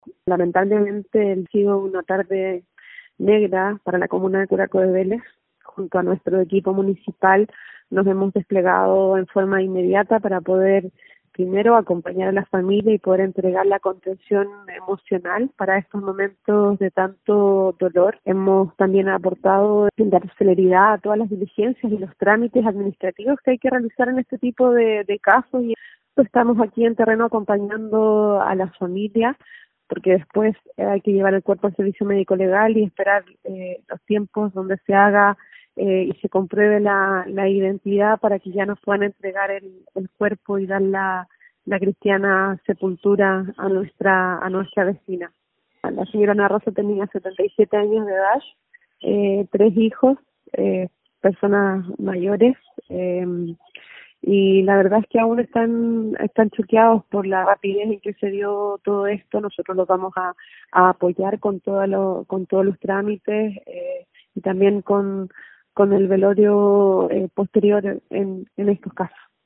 El hecho ocurrido este jueves por la tarde conmocionó a las localidades de Palqui y Huyar Bajo de la comuna de Curaco de Vélez, ante el triste desenlace que tuvo este incendio, que terminó desafortunadamente con la vida de esta vecina del lugar, pesar que compartió la alcaldesa de Curaco de Vélez, Javiera Yáñez.